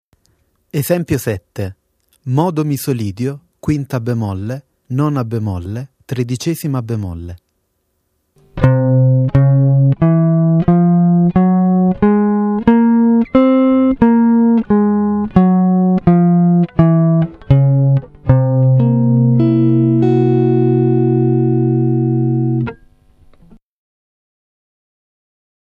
7(b5) con Misolidio b5 b9 b13